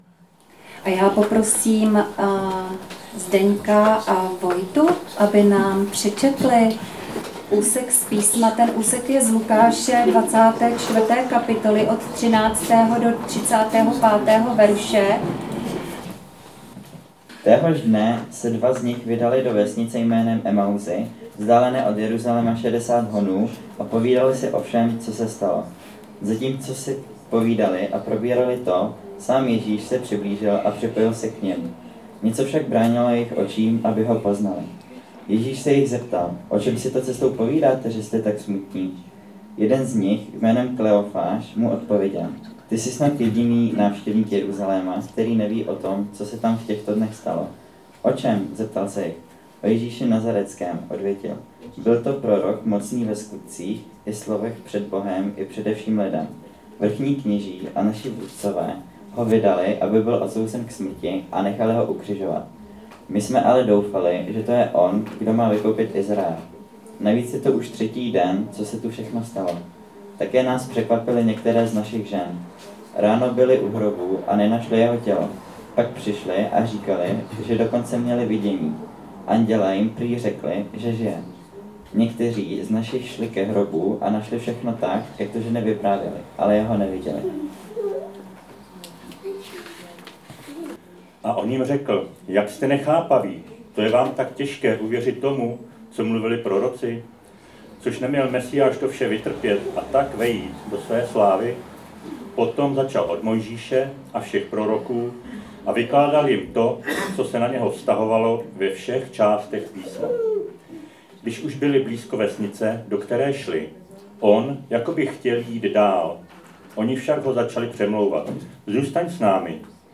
Kázání – 9. stránka – ECM Jihlava